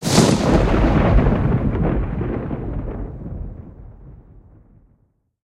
raskat-groma.ogg